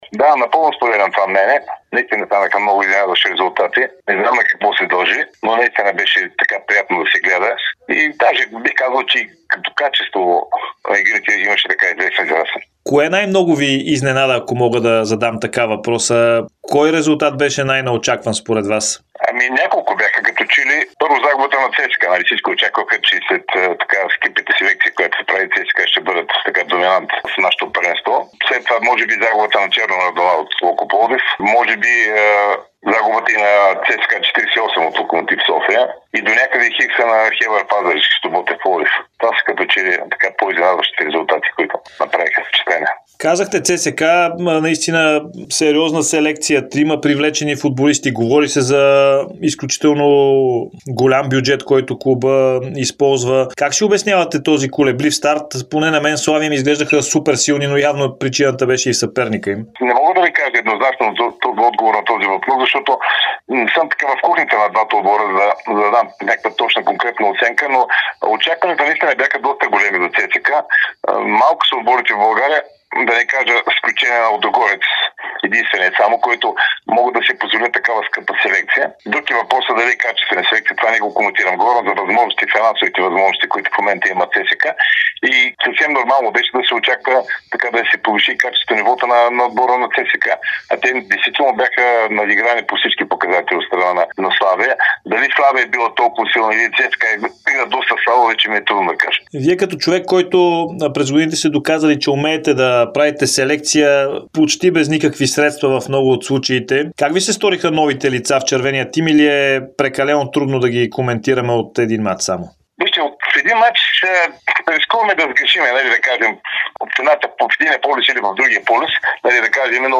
Треньорът Димитър Димитров - Херо говори ексклузивно пред Дарик радио и dsport относно впечатленията си от първия кръг от пролетния дял в efbet Лига, очакванията до края на сезона, селекцията в ЦСКА, победата на Левски над Лудогорец, съдийството, интересните новини около българските отбори, както и би ли се завърнал към професията.